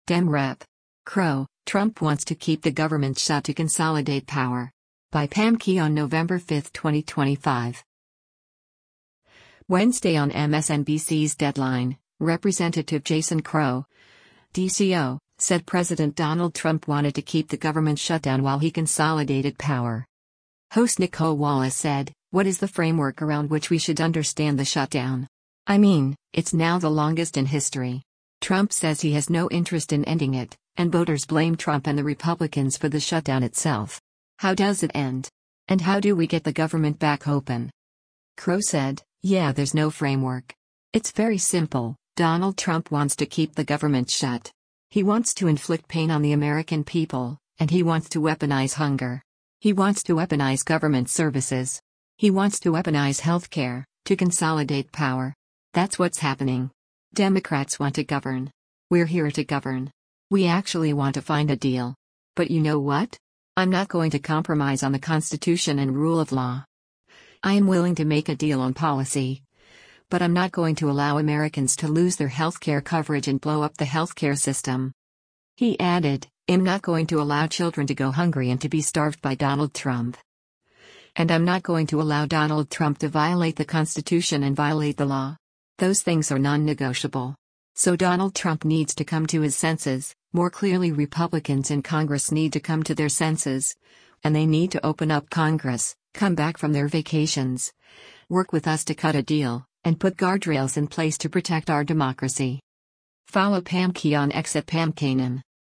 Wednesday on MSNBC’s “Deadline,” Rep. Jason Crow (D-CO) said President Donald Trump wanted to keep the government shutdown while he consolidated power.